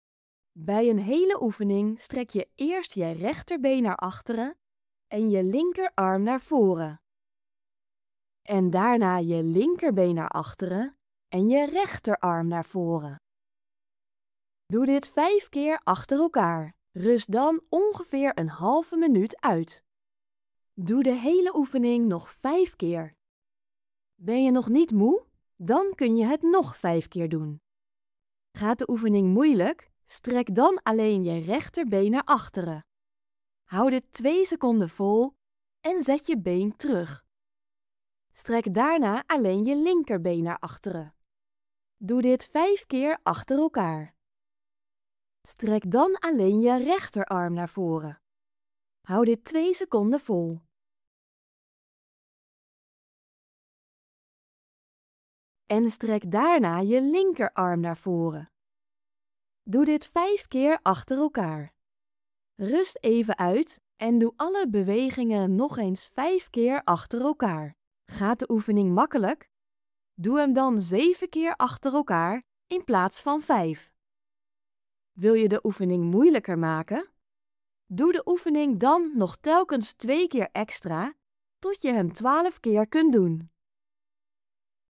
Geluidsbestand voor als je slecht ziet of blind bent